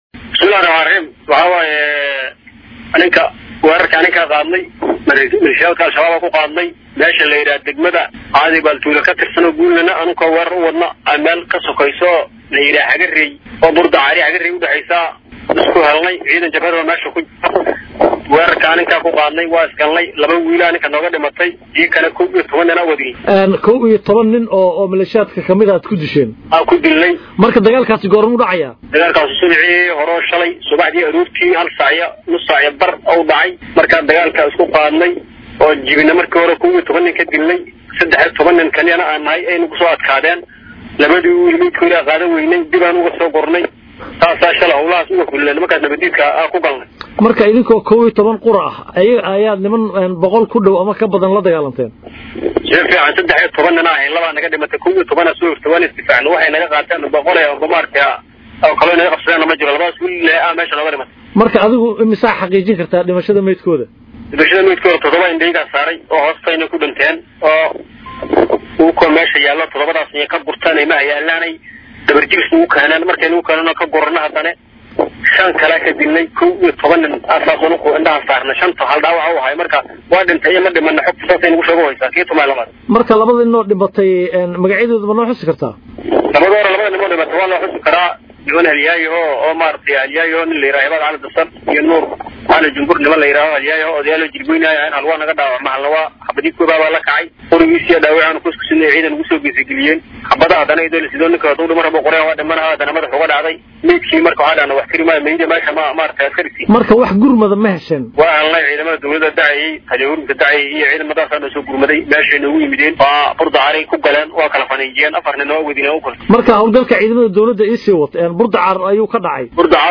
Codka Taliyaha https